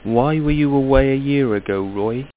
• Listen to the resultant speech, -